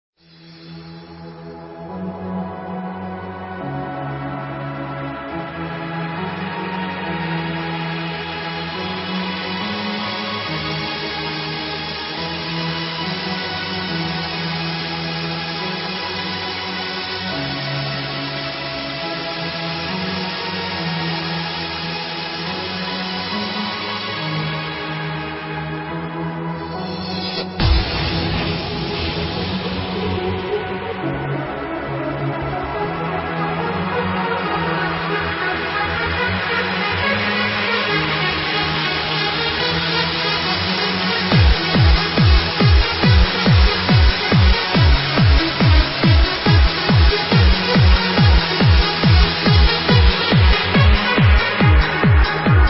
Wow, that sounds really amazing.